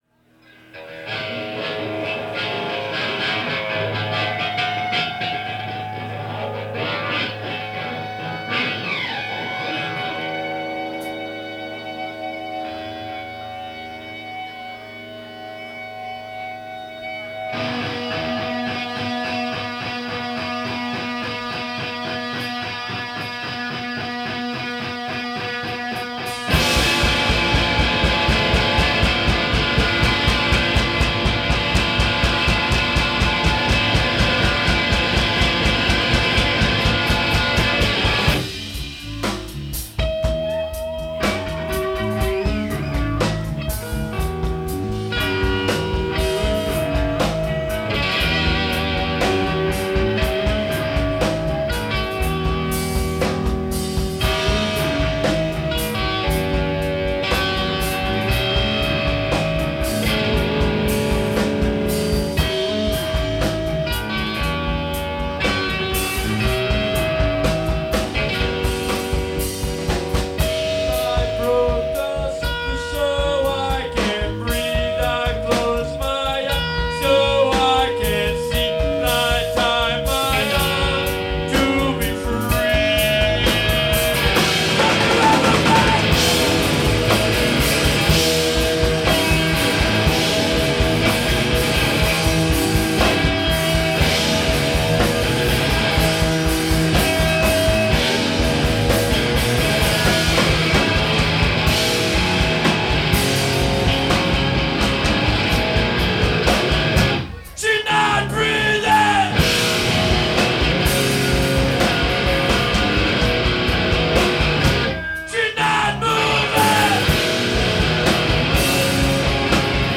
Live at Border
in Burlington, Vermont